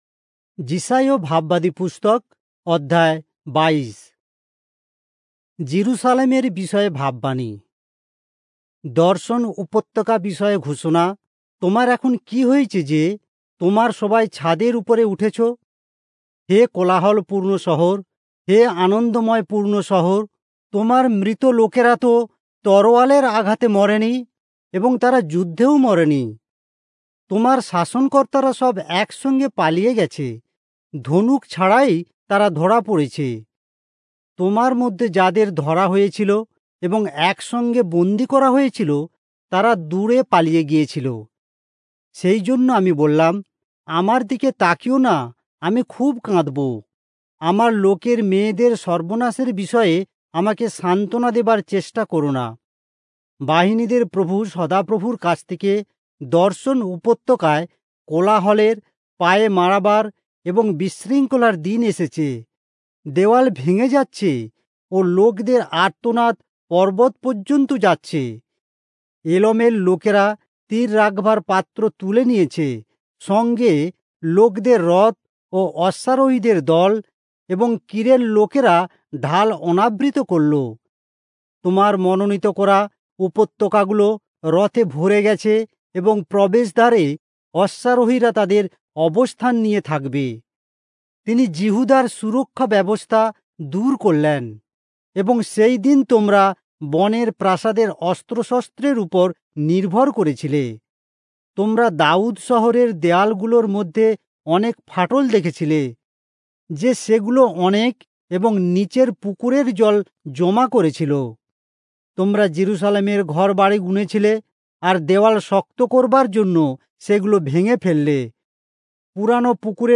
Bengali Audio Bible - Isaiah 12 in Irvbn bible version